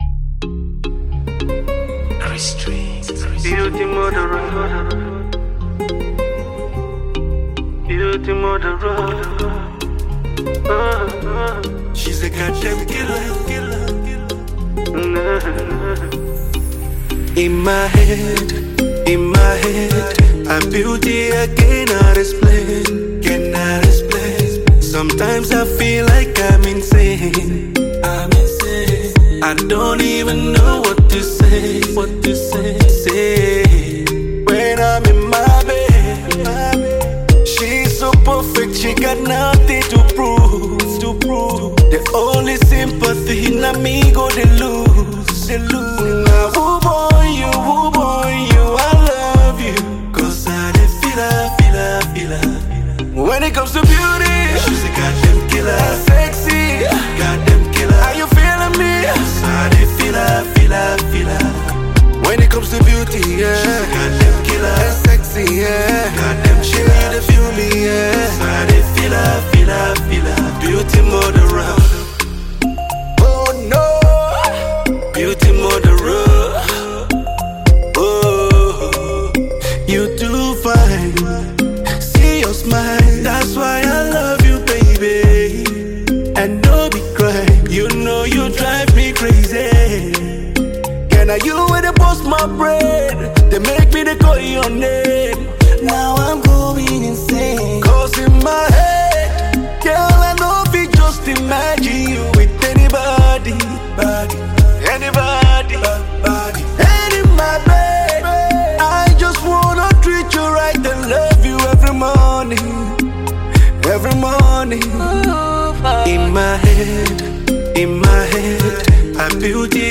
Talented Nigerian singer and songwriter